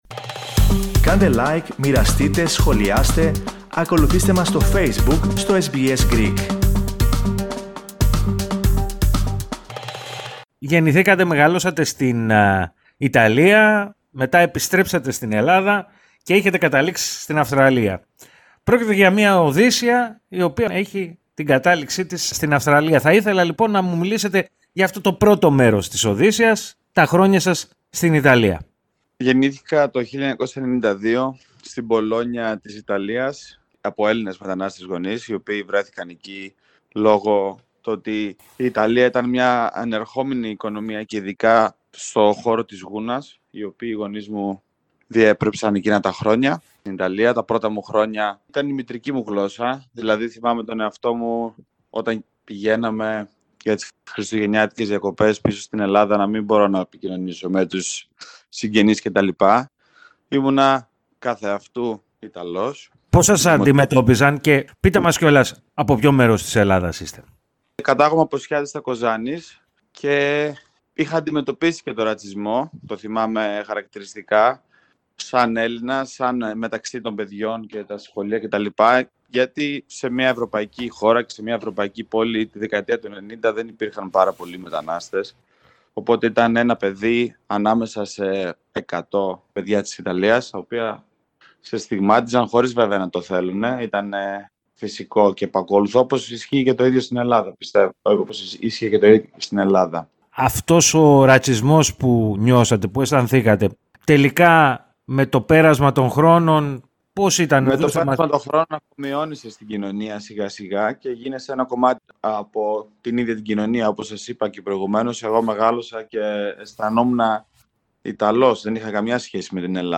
Βρήκε, άραγε, την Ιθάκη, που αναζητούσε; Ακούστε τη συνέντευξη, πατώντας το σύμβολο στο μέσο της κεντρικής φωτογραφίας.